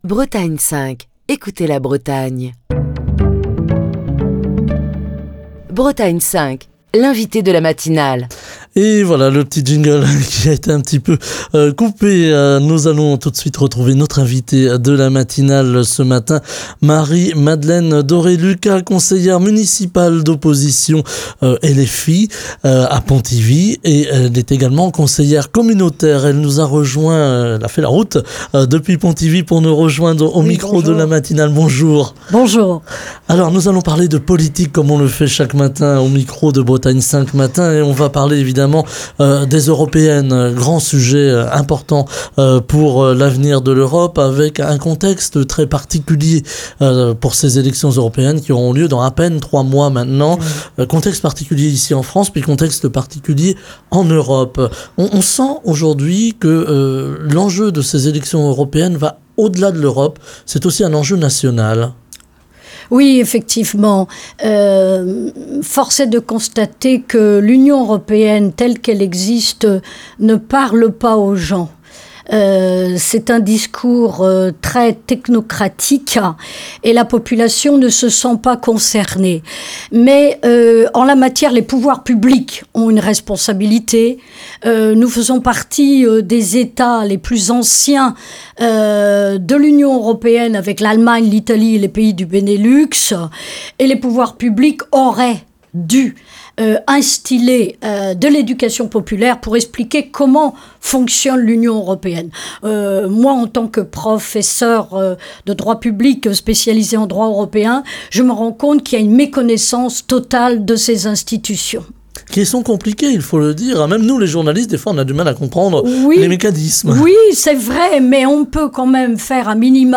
Marie-Madeleine Doré-Lucas, conseillère municipale d'opposition (LFI) à Pontivy, conseillère communautaire de Pontivy Communauté est l'invitée politique de Bretagne 5 Matin. Elle évoque la campagne de LFI pour les européennes et plus largement le contexte dans lequel démarre cette campagne sur fond de conflit Ukrainien et de crise sociale et économique, alors que de plus en plus de français réclament une Union européenne moins technocratique et plus démocratique.